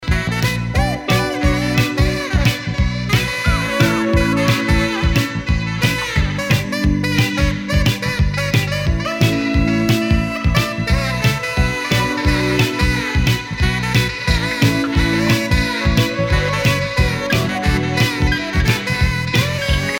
Il a eu du succès le saxo :)